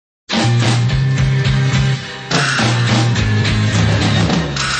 Listen to the opening drums and guitars, as one example.
The beat starts, intensifies, and then stops.